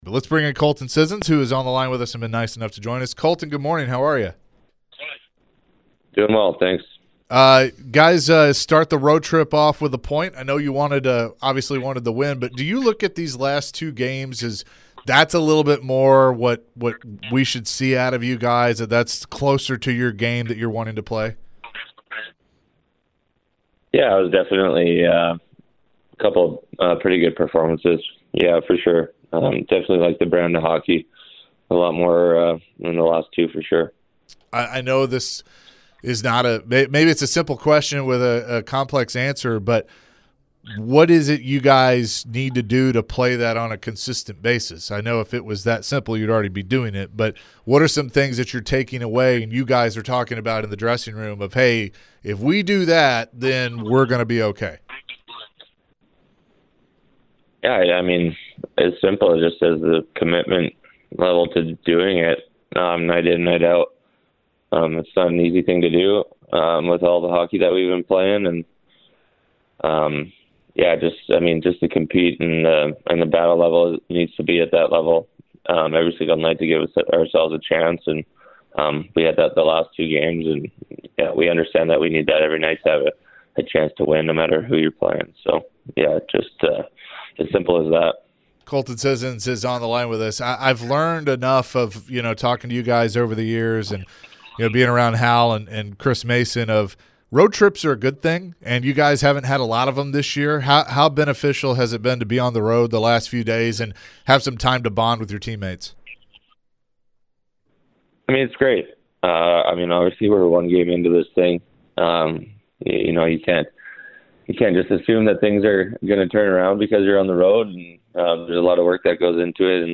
Colton was asked about playing in Andrew Brunette's system and the adjustment players are making. Colton was also asked about the team's slow start to the season and expressed his will that team looks to turn things around.